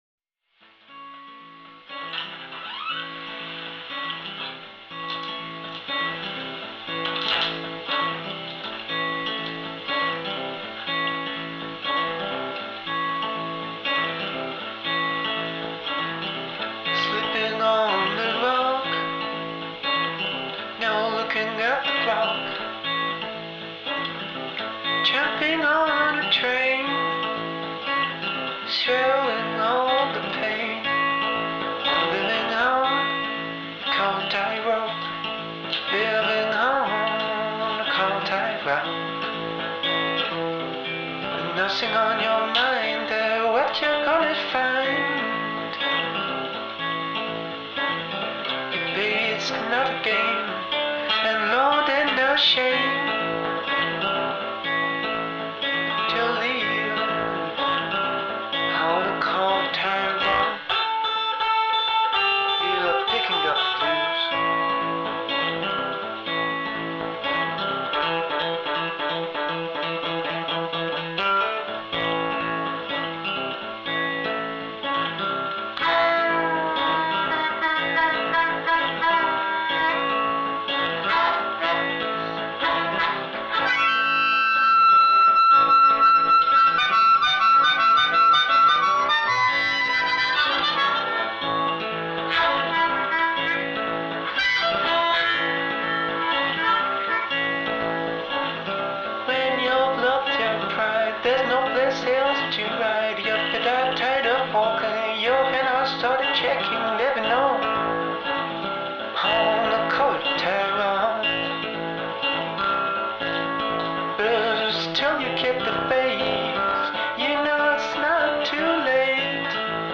évolue dans un registre folk pop minimaliste
chansons folk intimiste